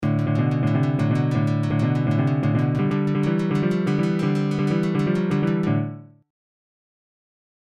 ??? > Blues Licks
Blues+Licks.mp3